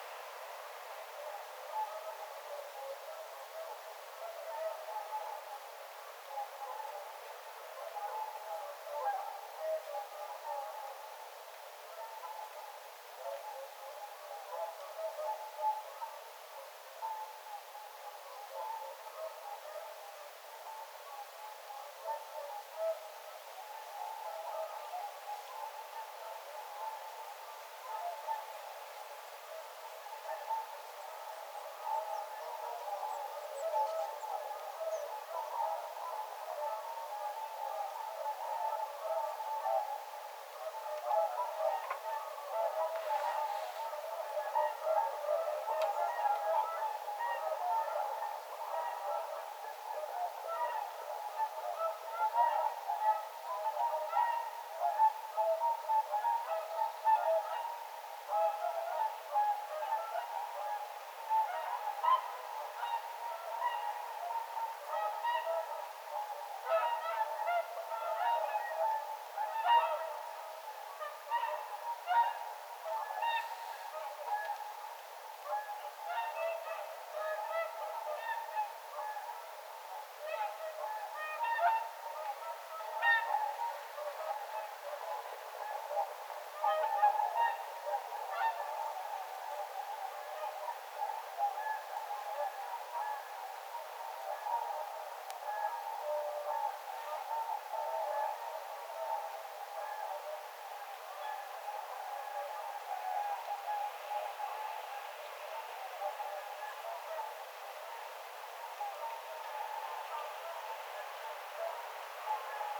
laulujoutsenten ääntelyä lahdella
vahan_laulujoutsenten_aantelya_isolla_lintulahdella.mp3